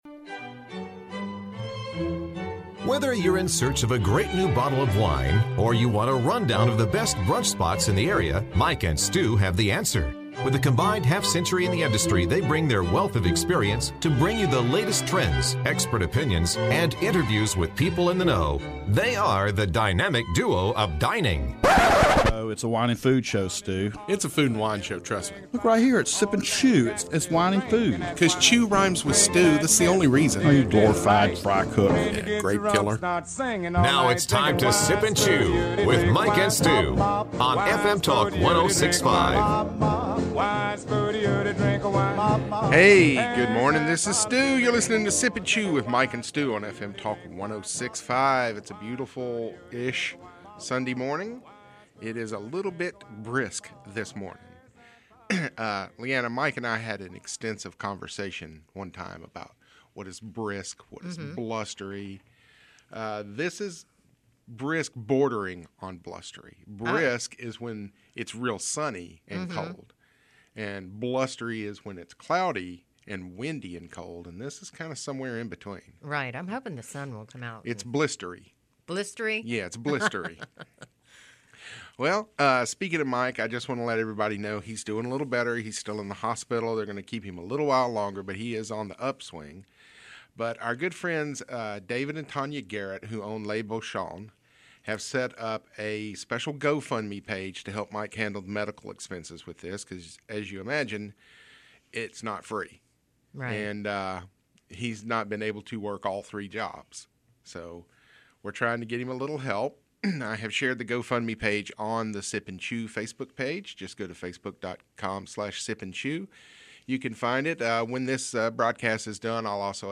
weekly foodie show sundays 8 to 9 AM on FM TALK 106.5 in Mobile